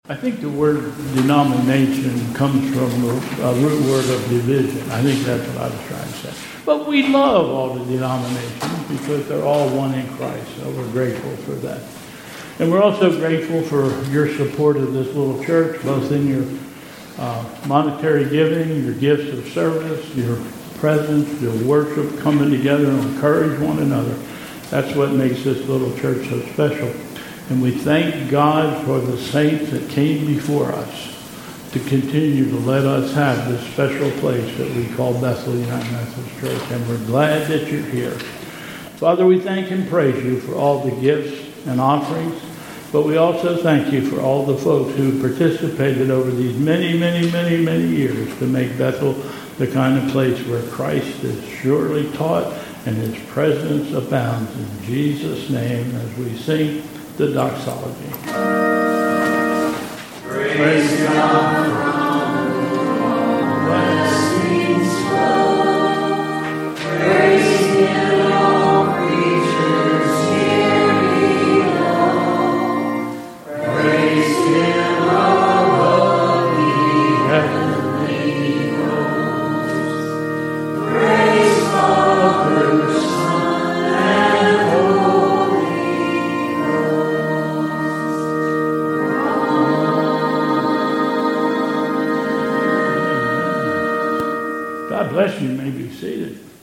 Bethel Church Service
Call to Worship...
...Affirmation...and...Gloria Patria